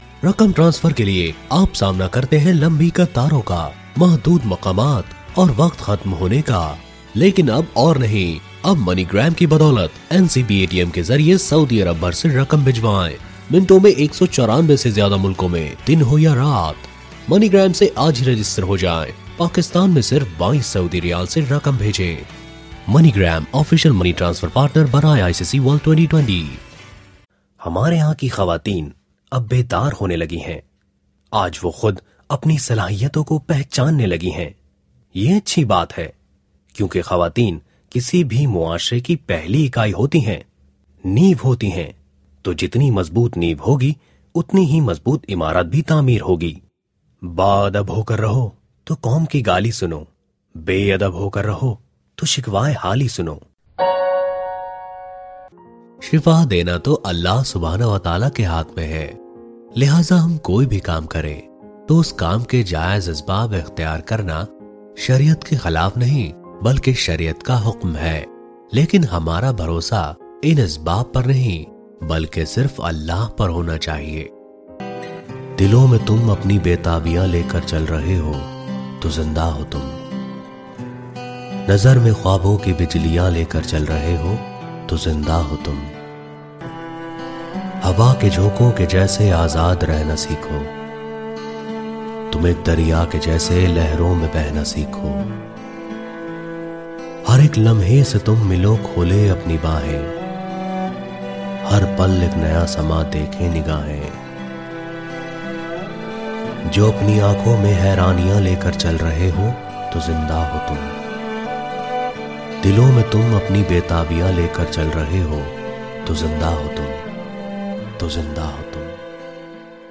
Urduca Seslendirme
Erkek Ses